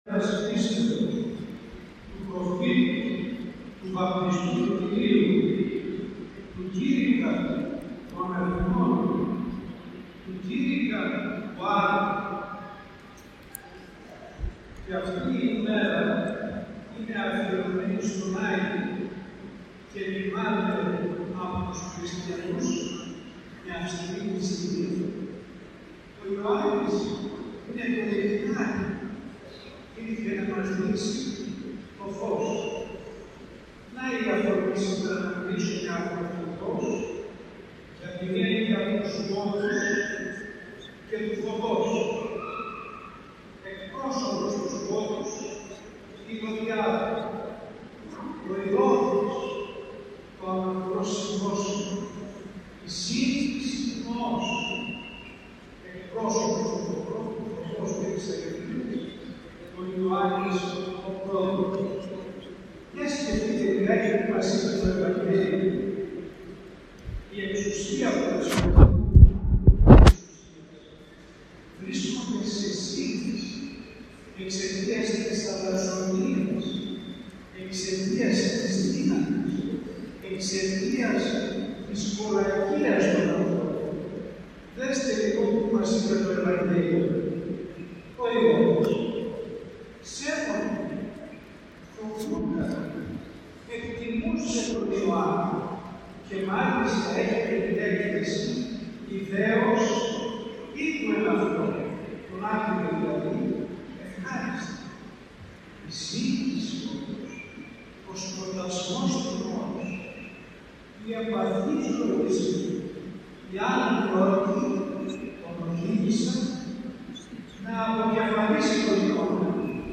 κήρυγμά